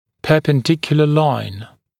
[ˌpɜːpən’dɪkjulə laɪn][ˌпё:пэн’дикйулэ лайн]перпендикулярная линия